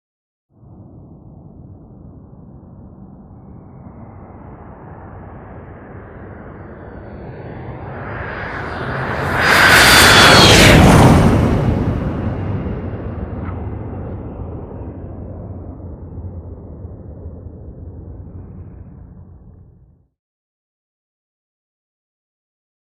F-5: Land / Overhead; Rumbling Approach, Swishing Hiss With By, Landing Jets Ignite With Distant Wheel Touchdown. Jet.